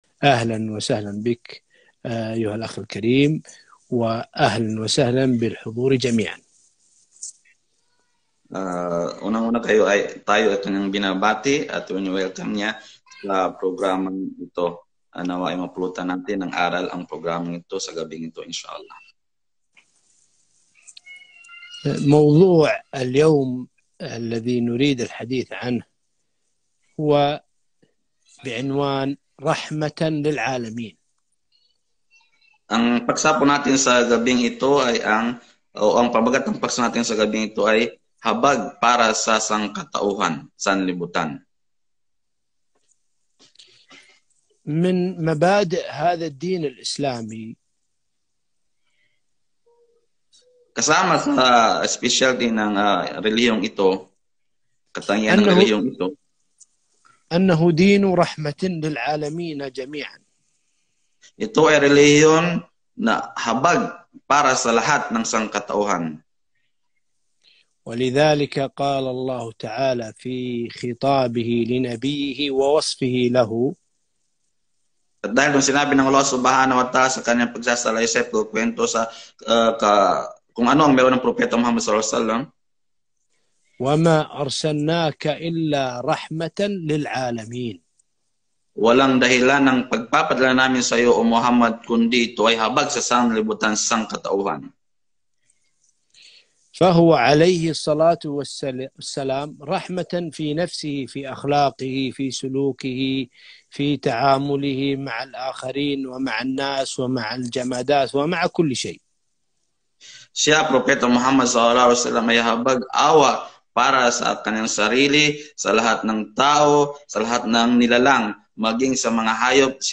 محاضرة بعنوان « رحمة للعالمين » مترجمة للجالية الفلبينية